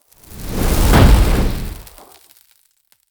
spell-impact-4.mp3